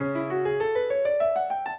minuet5-6.wav